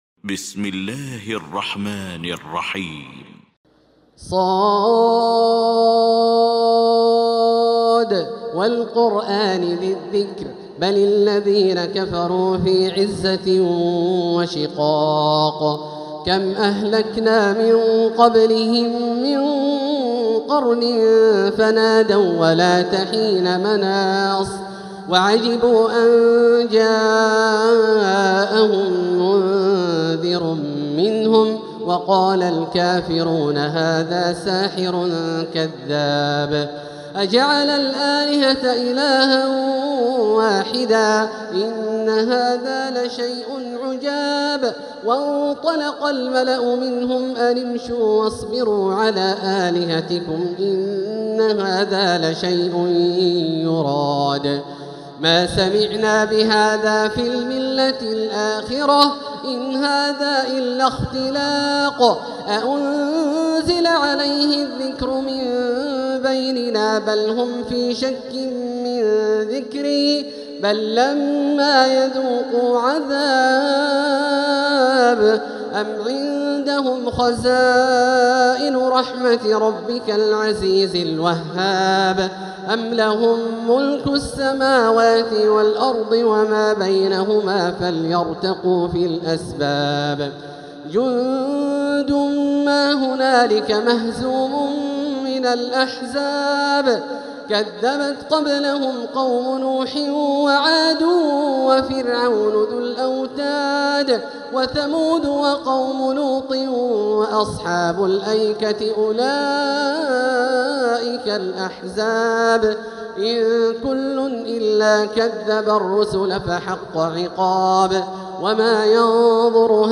المكان: المسجد الحرام الشيخ: معالي الشيخ أ.د. بندر بليلة معالي الشيخ أ.د. بندر بليلة فضيلة الشيخ عبدالله الجهني ص